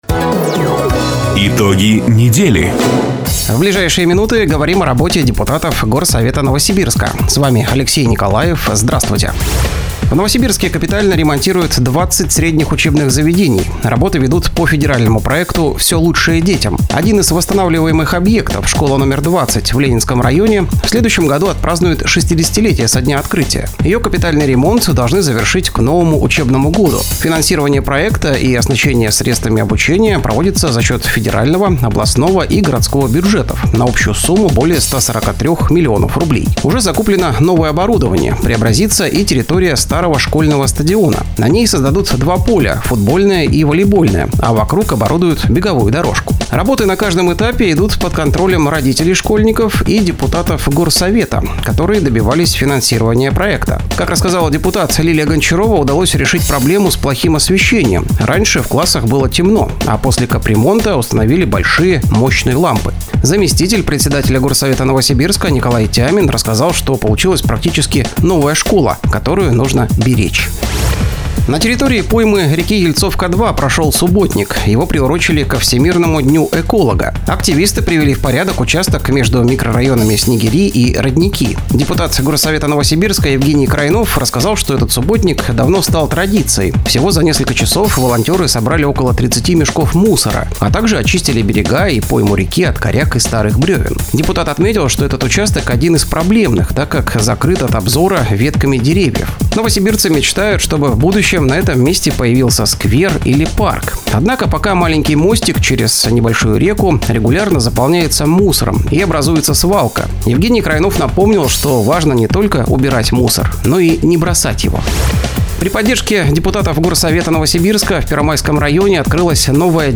Запись программы "Итоги недели", транслированной радио "Дача" 21 июня 2025 года